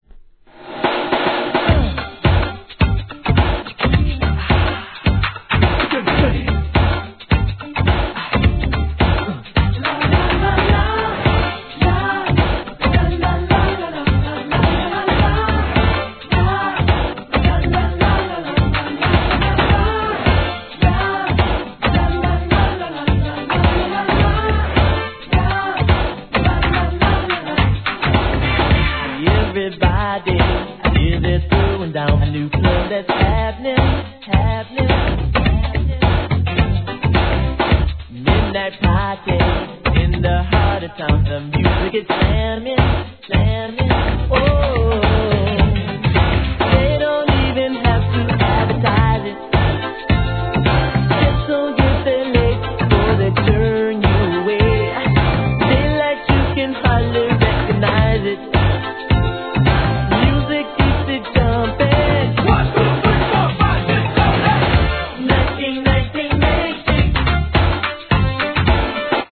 CLUB MIX
HIP HOP/R&B
初期NEW JACK SWINGでDISCOヒット!